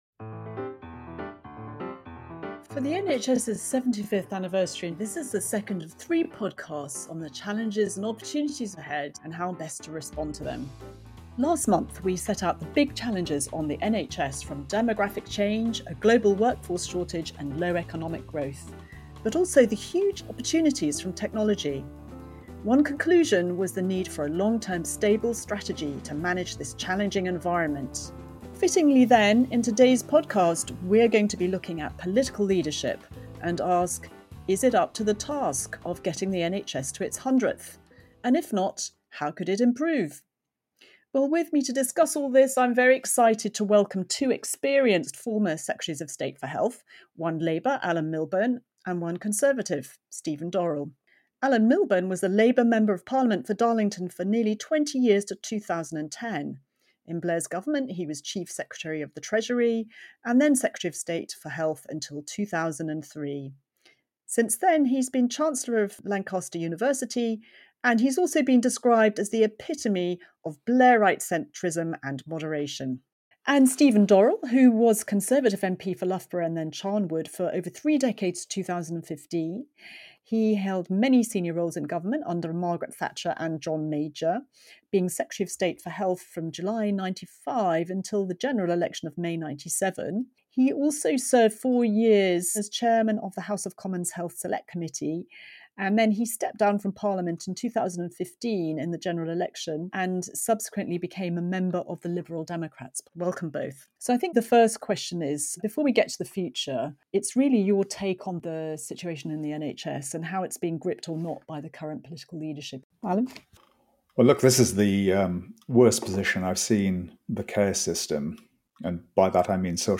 Interviews with experts and high-profile guests discussing the most important issues affecting the future of health and care for people in the UK.